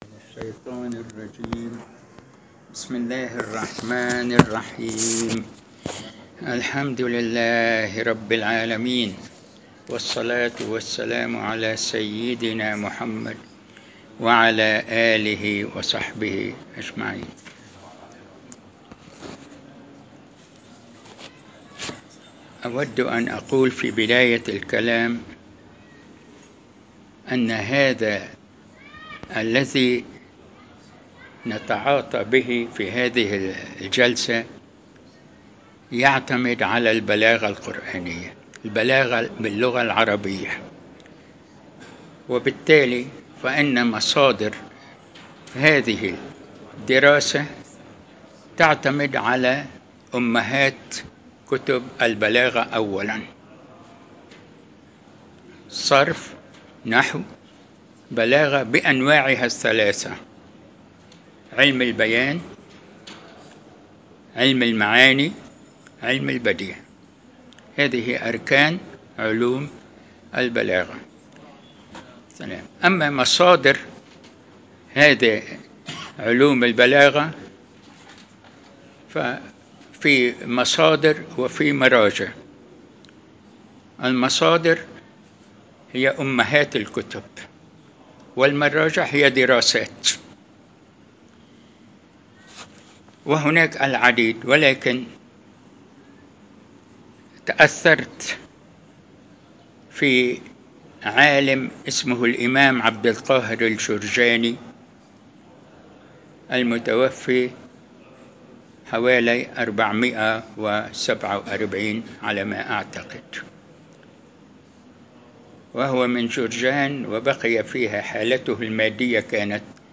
المكان : المسجد البحري الموضوع : إتمام درس ” التعليل البلاغي والجملة الإستئنافية ” \ البدء بدرس : ” مراعاة النظير ”